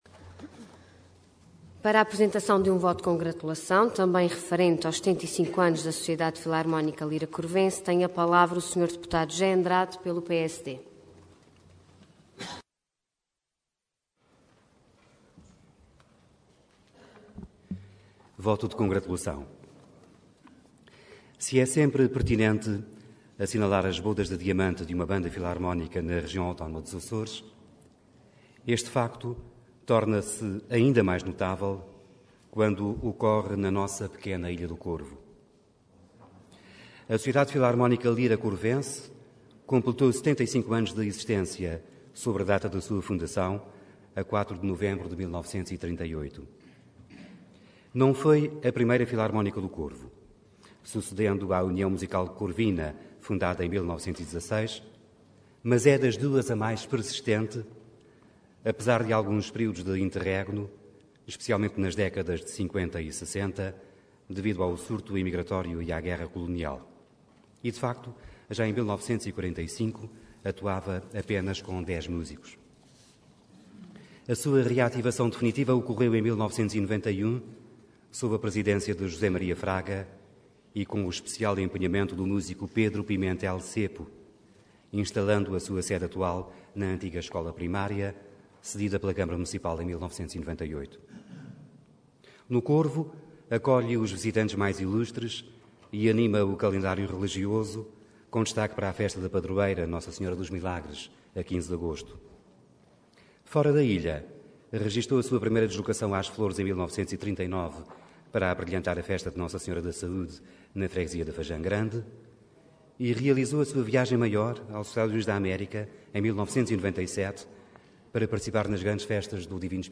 Detalhe de vídeo 10 de dezembro de 2013 Download áudio Download vídeo Processo X Legislatura 75 Anos da Sociedade Filarmónica Lira Corvense Intervenção Voto de Congratulação Orador José Andrade Cargo Deputado Entidade PSD